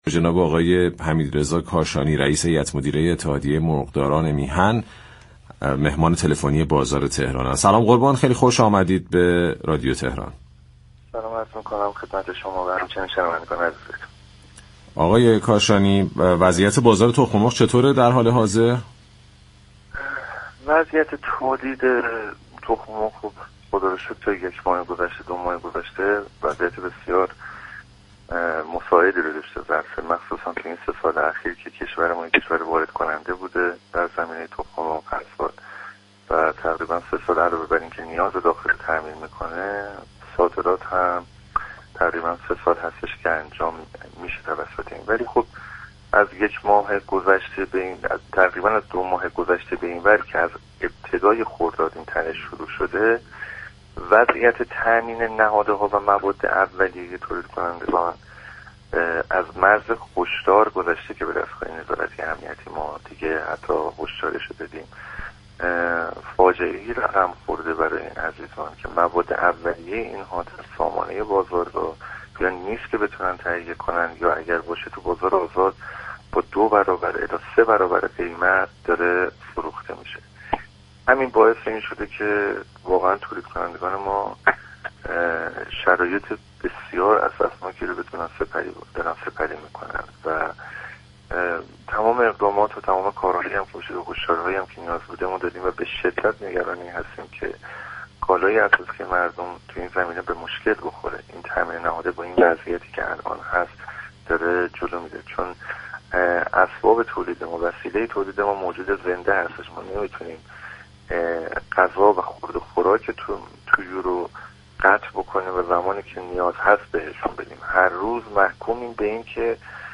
برنامه«بازار تهران»، روزهای شنبه تا چهارشنبه 11 تا 11:55 از رادیو تهران پخش می‌شود.